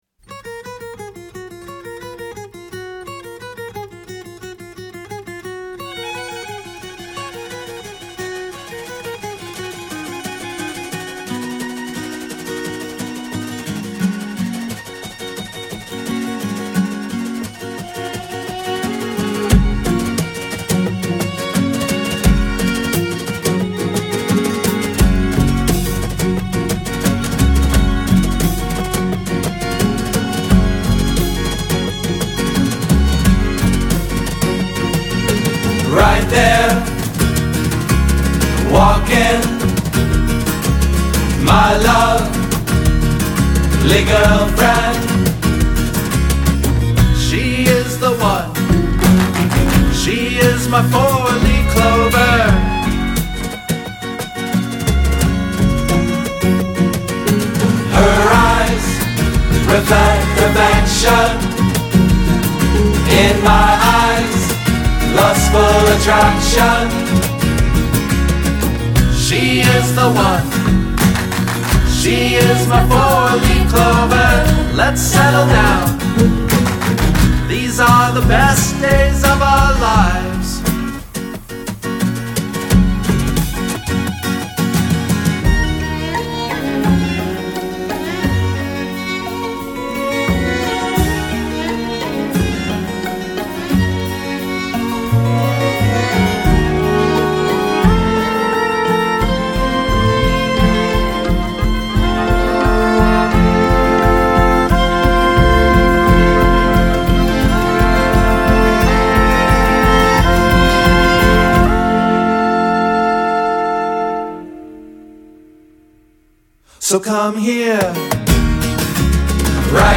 San Francisco-based duo
returns with a revitalized organic sound